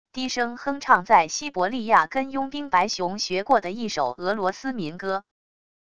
低声哼唱在西伯利亚跟佣兵白熊学过的一首俄罗斯民歌wav音频